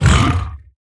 1.21.5 / assets / minecraft / sounds / mob / zoglin / hurt3.ogg
hurt3.ogg